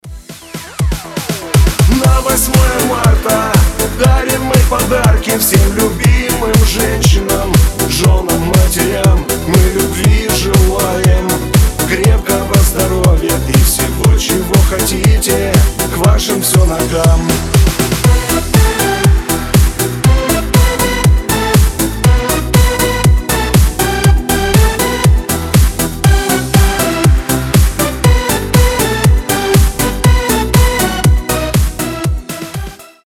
добрые
праздничные